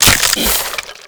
death.ogg